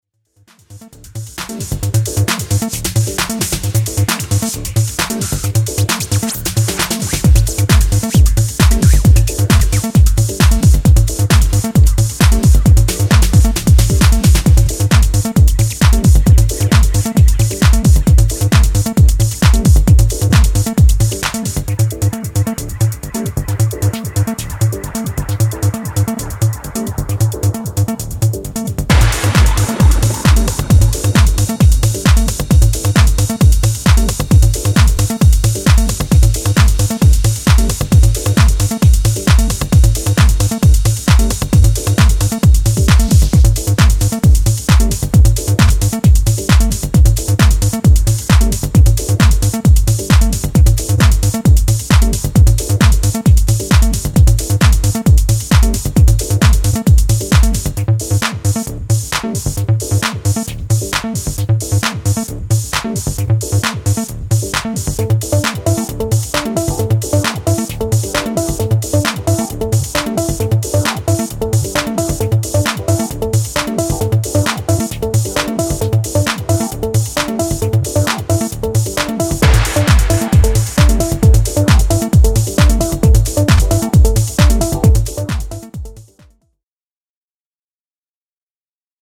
supplier of essential dance music
House Techno